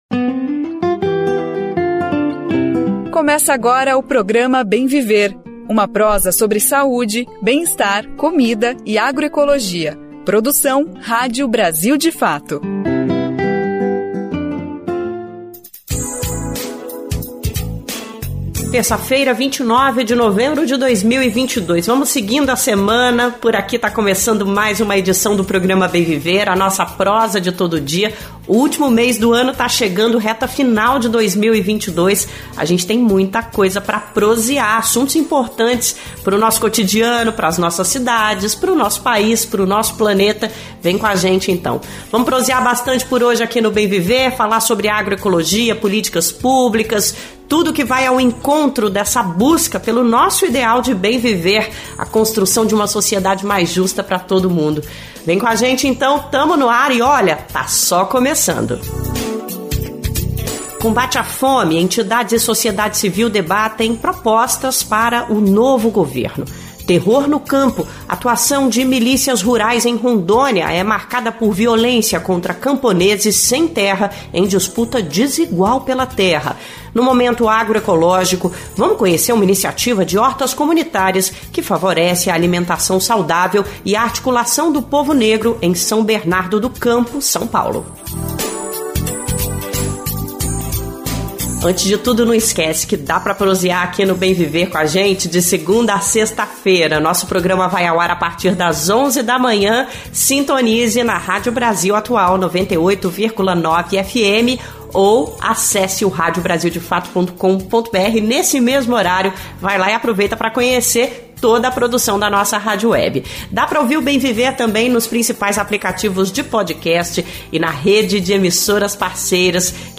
Conversa Bem Viver